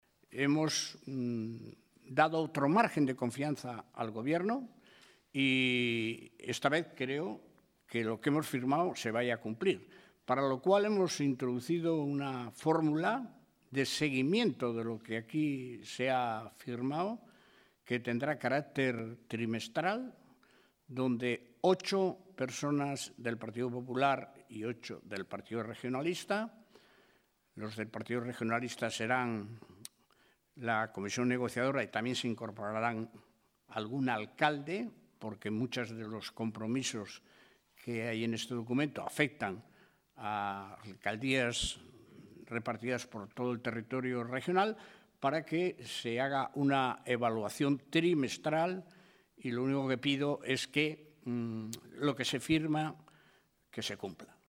En una comparecencia ante los medios de comunicación tras la firma del documento, Revilla ha reconocido que este apoyo "no ha sido fácil" por el "precedente de incumplimiento" de muchos de los acuerdos comprometidos el año pasado, aunque el PRC ha apostado por conceder "un margen de comprensión" al Gobierno por la dificultad que conlleva la puesta en marcha de los proyectos en el primer año de la legislatura.
Ver declaraciones de Miguel Ángel Revilla, secretario general del Partido Regionalista de Cantabria.
Audio Miguel Ángel Revilla